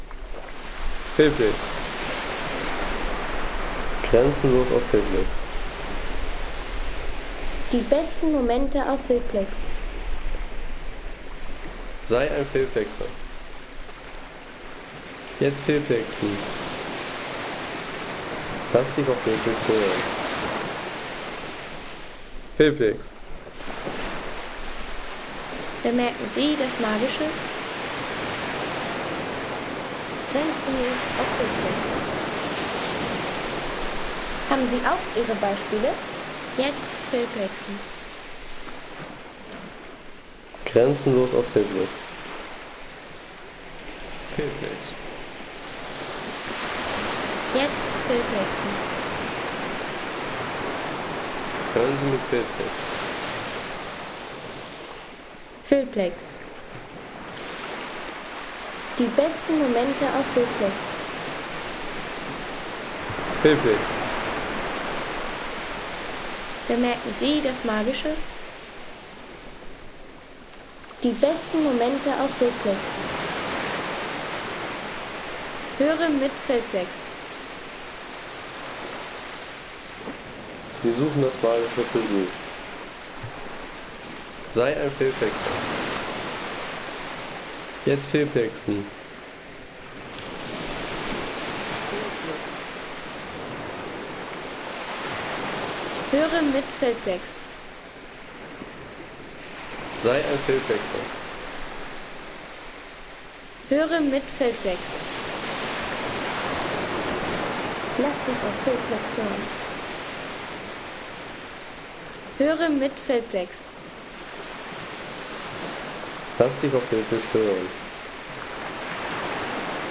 Wellenklänge vom Atlantik in San Agustin | Feelplex
Atlantikwellen mit Gran-Canaria-Gelassenheit
Atmosphärische Atlantikwellen aus San Agustin auf Gran Canaria.
Natürliche Atlantik-Kulisse aus San Agustin mit sandigem Strandgefühl und entspannter Küstenatmosphäre für Film und Hintergrundszenen.